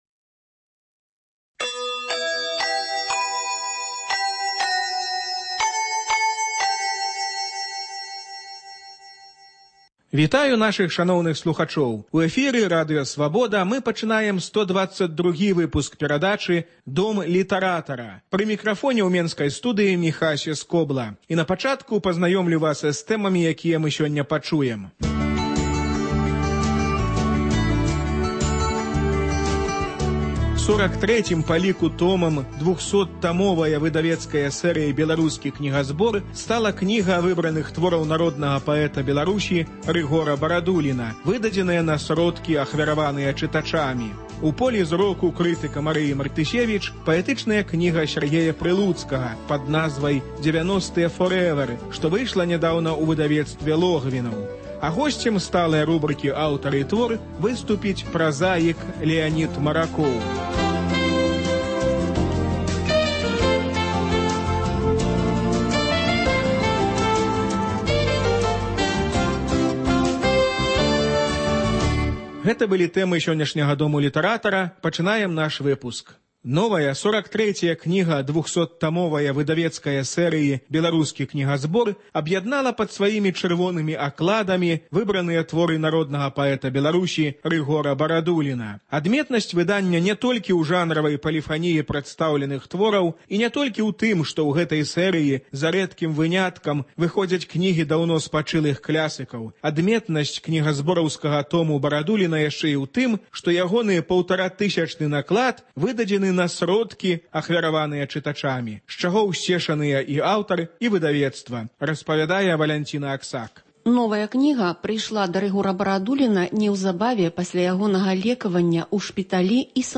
Літаратурны агляд з удзелам Рыгора Барадуліна